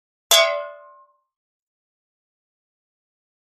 Metal Pan Ping Hit, Type 5